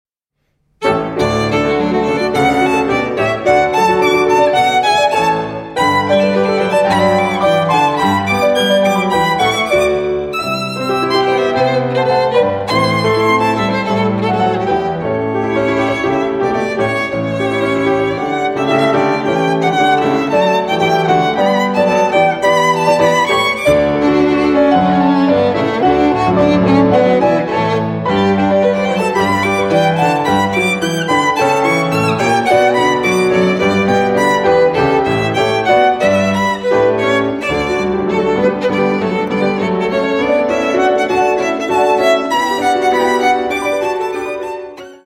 Allegro giojoso (8:27)